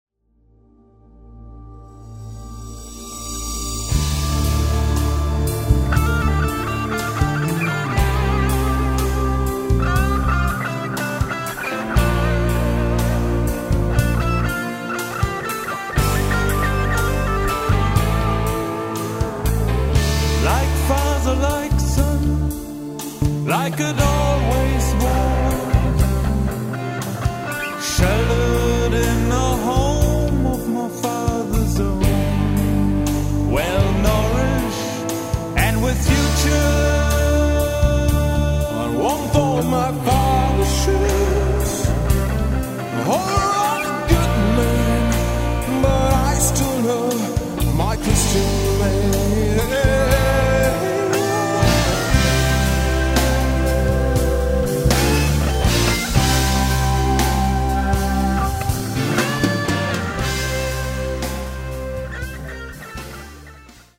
aufgenommen und abgemischt im eigenem
Eine melodiöse Pop-Rock-Produktion.
rockigen und folkigen Gitarrenelementen.